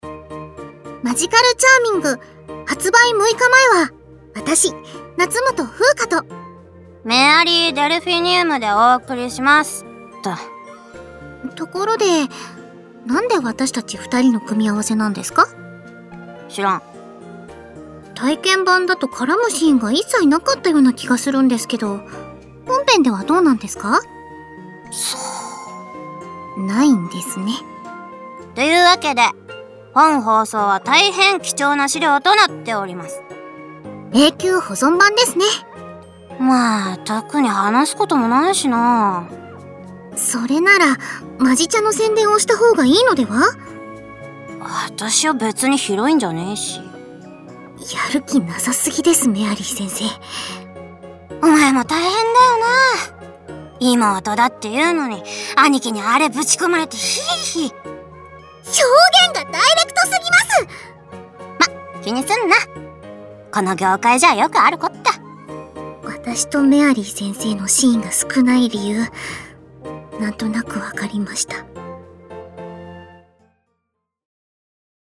発売六日前カウントダウンボイス公開！